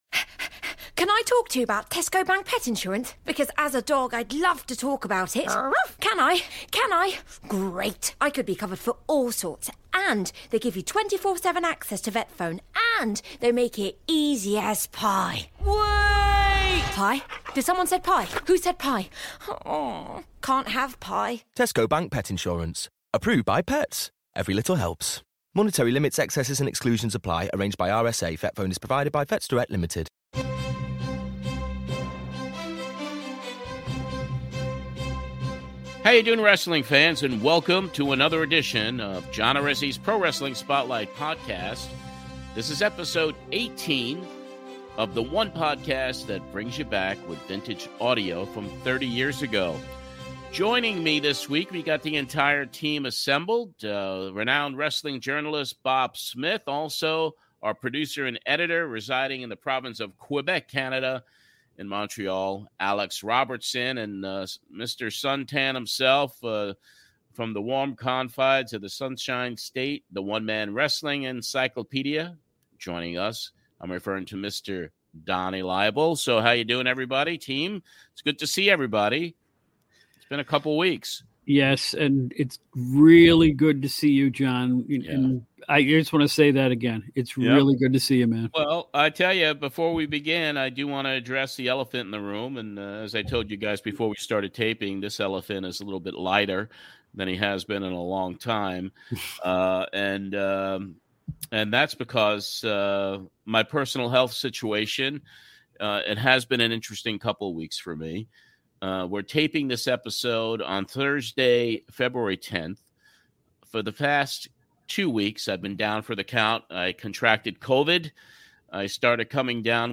This episode will cover the original broadcast of the Pro Wrestling Spotlight, which aired on February 16th, 1992, on 1050 AM WEVD in New York, NY.